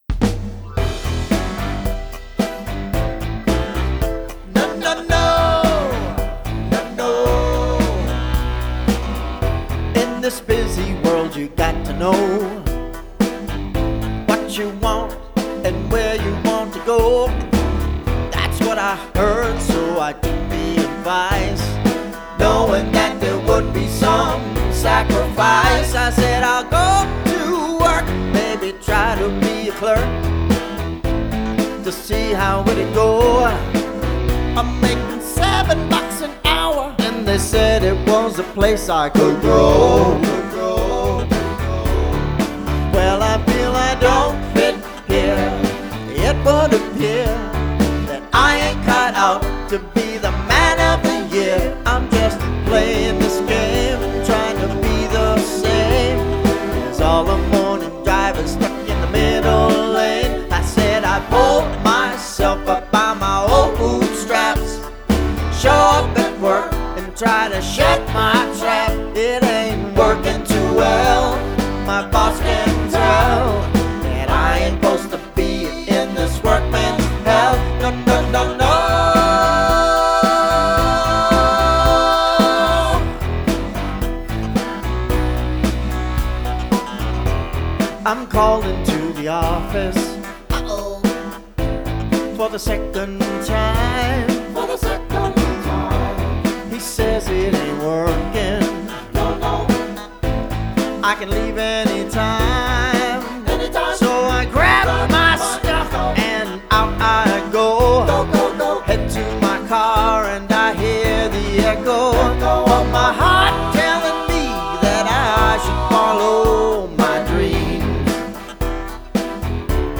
Тип альбома: Студийный
Жанр: Blues-Rock, Soft-Rock